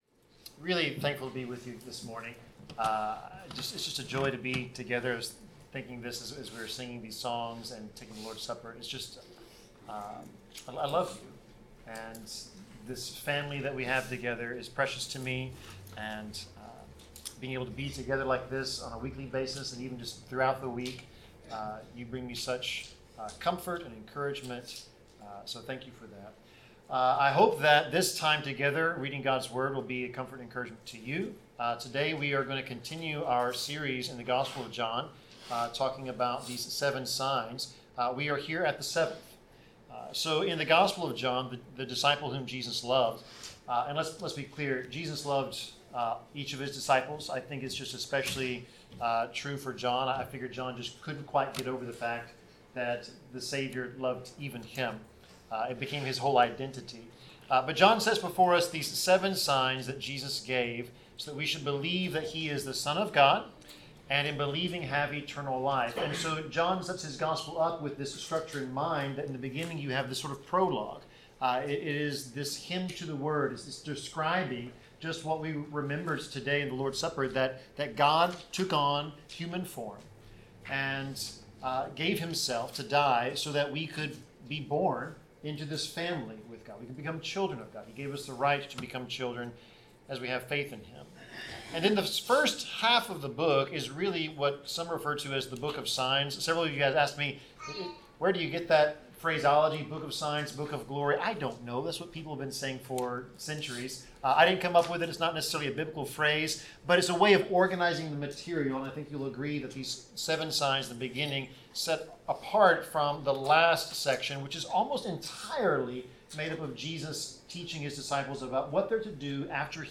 Passage: John 11:1-44 Service Type: Sermon